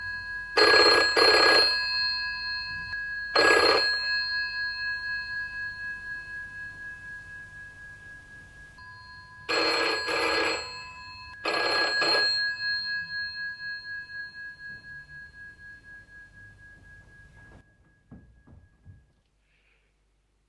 Telephone Bell WAV file
This is a UK telephone bell close, distant and medium, recorded in the 1950s
Uncompressed 44.1kHz 16 bit mono Wav file